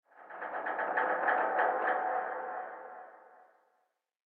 ambienturban_24.ogg